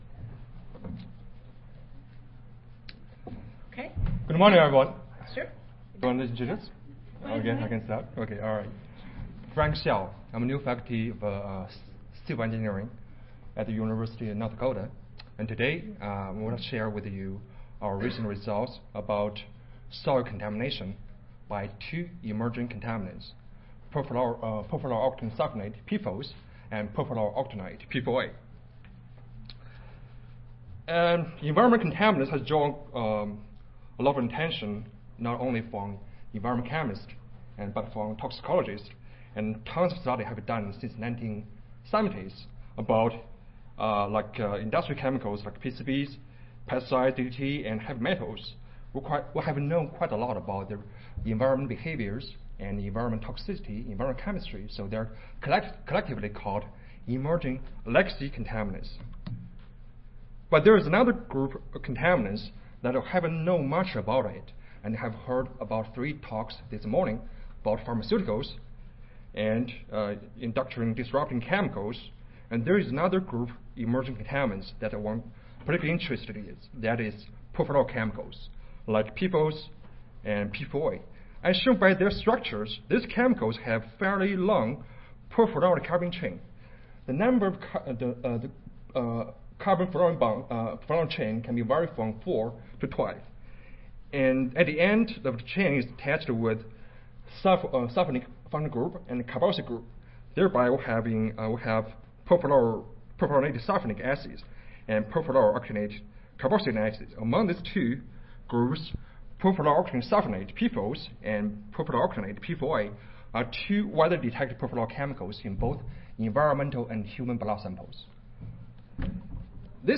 University of Minnesota Audio File Recorded Presentation